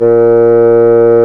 Index of /90_sSampleCDs/Roland L-CDX-03 Disk 1/WND_Bassoons/WND_Bassoon 2
WND BASSOO06.wav